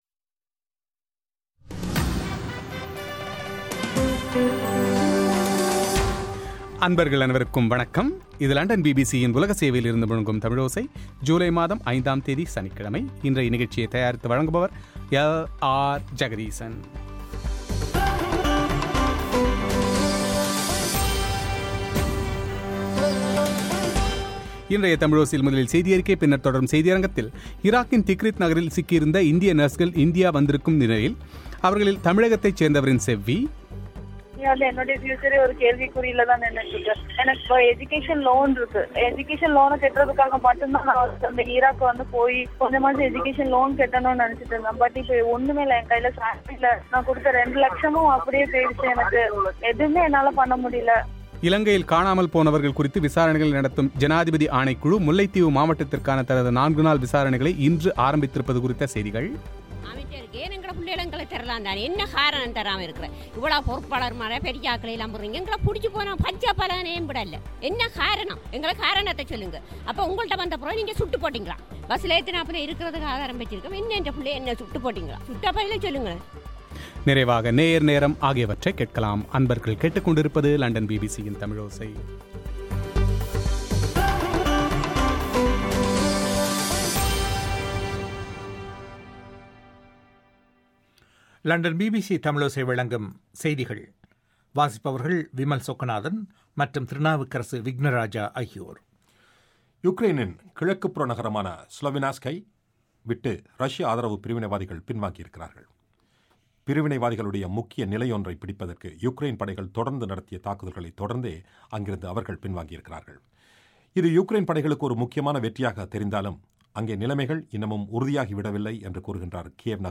பிரத்யேக செவ்வி